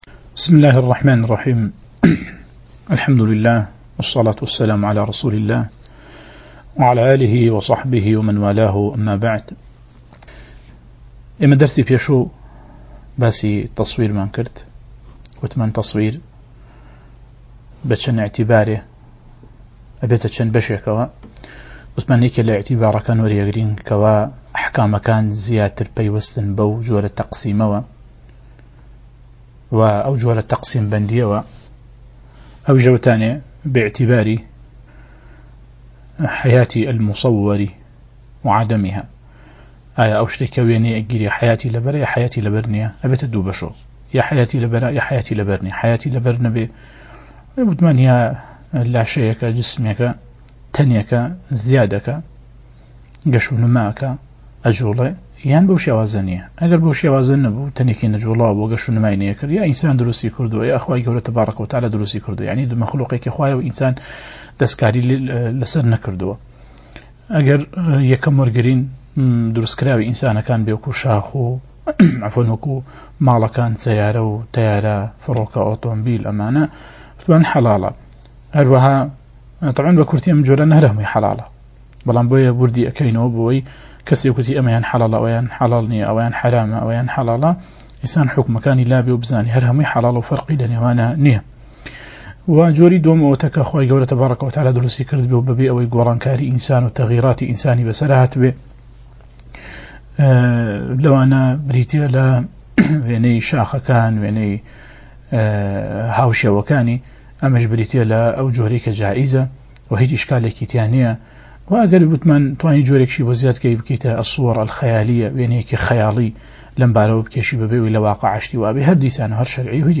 وانەی 5 - حوكمی وێنه‌ گرتن (التصوير) له‌ فيقهی ئيسلاميدا